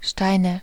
Ääntäminen
Synonyymit Geld Ääntäminen : IPA: [ˈʃtaɪ̯nə] Haettu sana löytyi näillä lähdekielillä: saksa Käännöksiä ei löytynyt valitulle kohdekielelle. Steine on sanan Stein monikko.